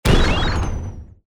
Bomb_2.mp3